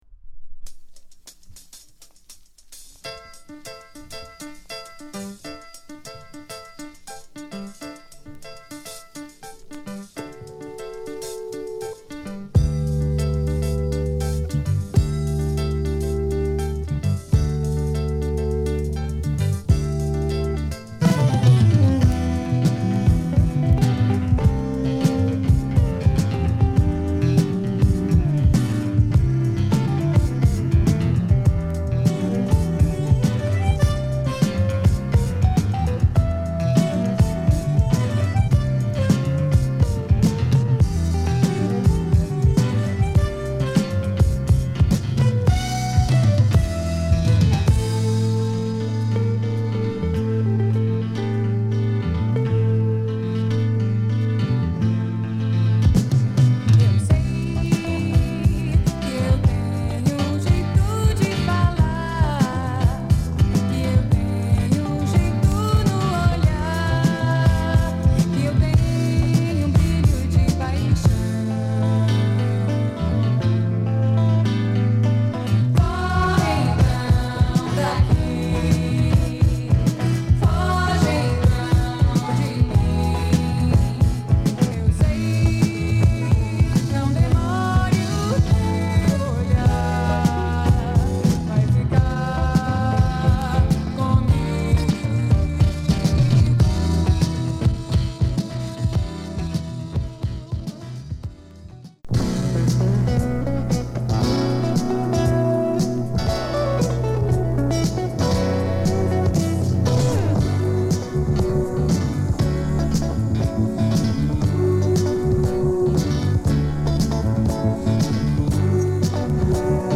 両面共にAORやシティポップにも通じる煌びやかでメロウ、それでいてドライブ感あふれる実に素晴らしいもの！